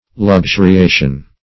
\Lux*u`ri*a"tion\
luxuriation.mp3